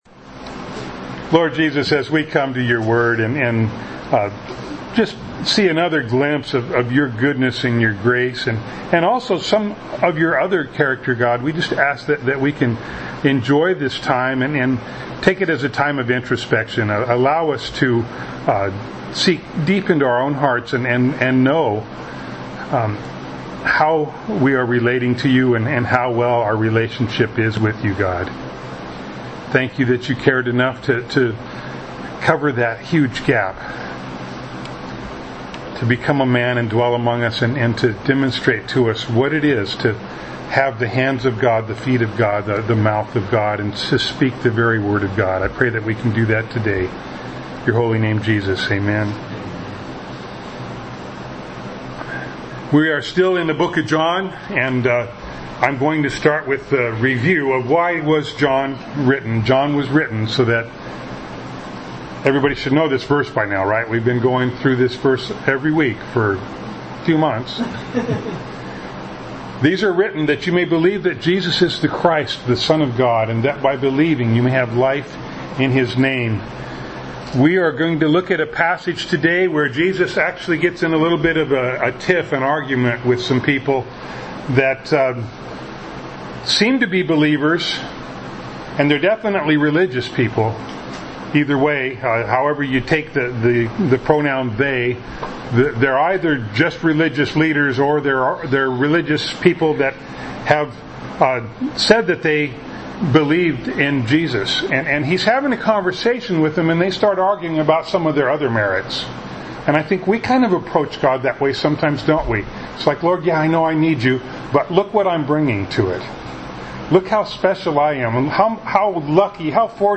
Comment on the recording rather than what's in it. John 8:31-59 Service Type: Sunday Morning Bible Text